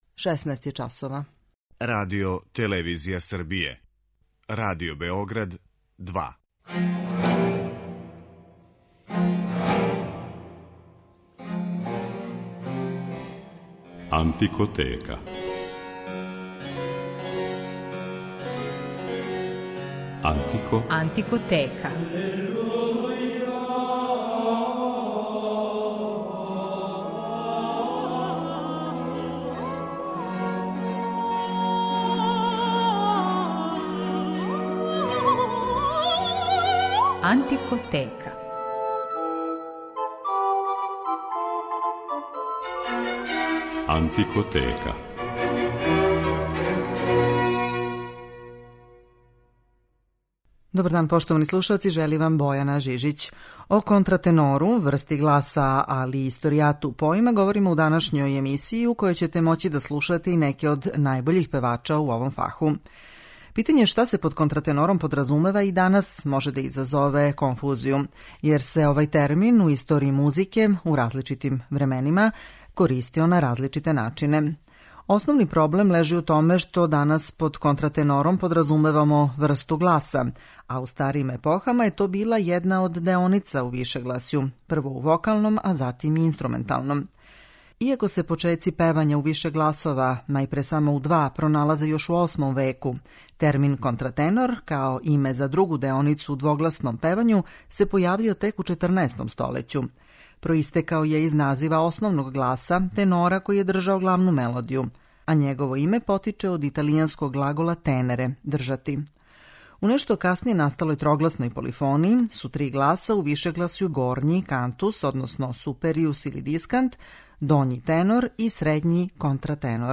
О контратенору као врсти гласа, али и историјату овог појма, говоримо у данашњој емисији, у којој ћете моћи да слушате и неке од најбољих певача у овом фаху.